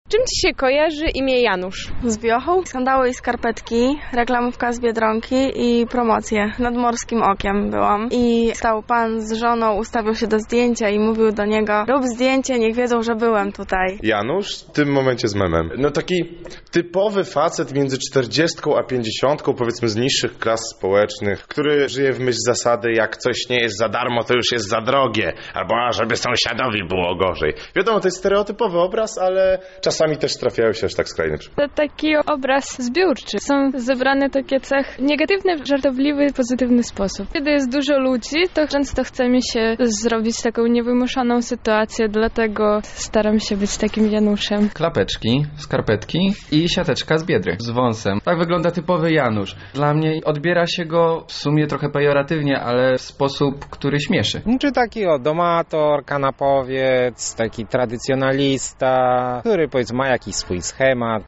Kim zatem jest typowy Janusz? Zapytaliśmy o to mieszkańców Lublina: